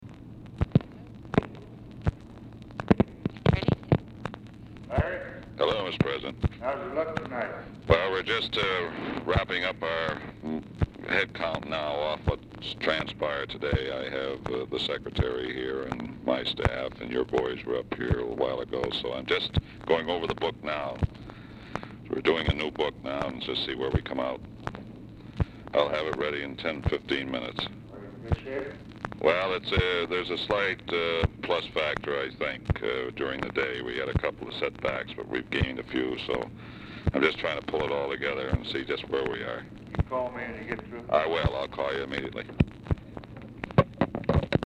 Telephone conversation # 2866, sound recording, LBJ and LARRY O'BRIEN, 4/6/1964, 7:00PM | Discover LBJ
Format Dictation belt
Specific Item Type Telephone conversation